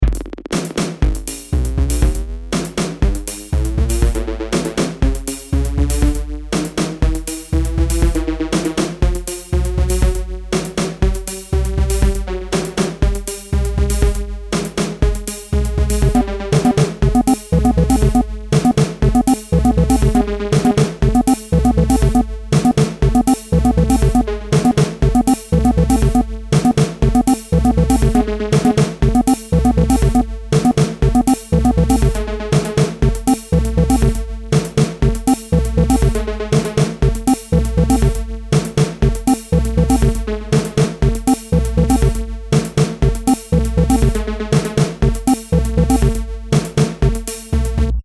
Bucle de Electro
Música electrónica pieza melodía repetitivo sintetizador